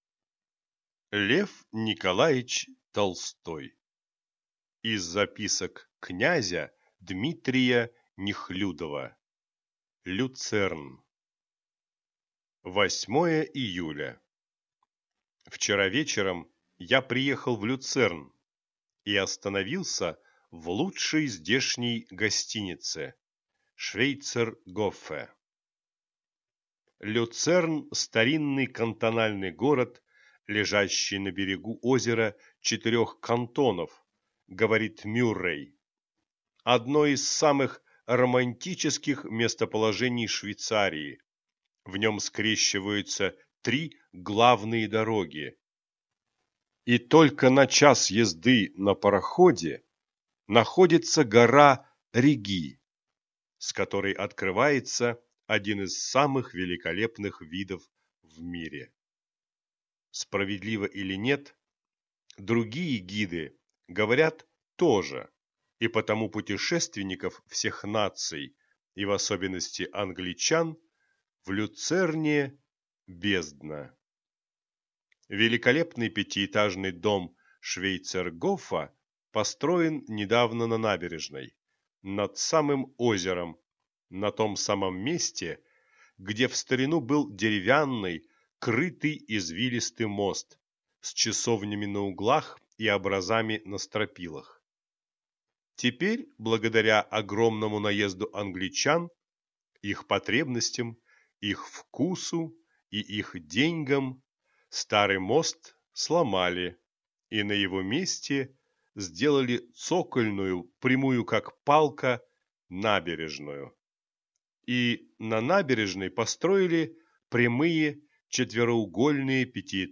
Аудиокнига Из записок князя Д.Нехлюдова (Люцерн) | Библиотека аудиокниг